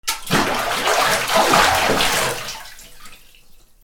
水をかき回す 短
『バシャバシャ』